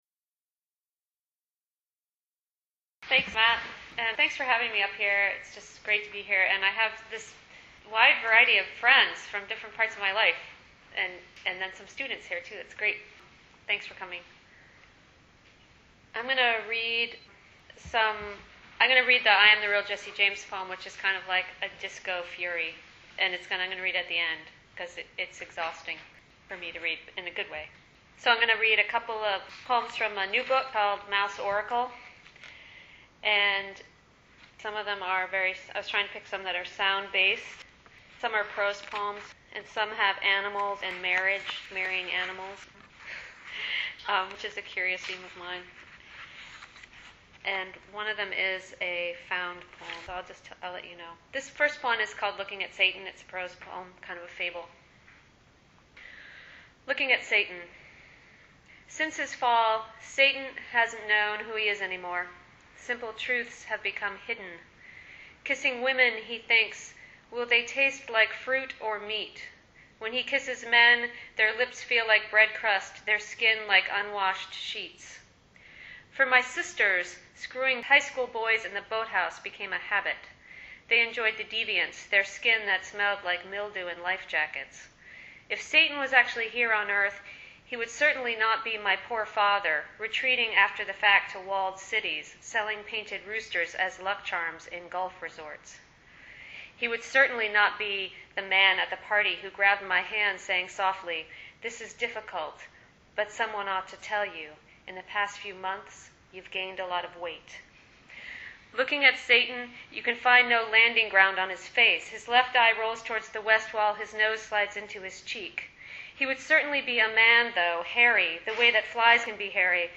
Full Bowdoin Reading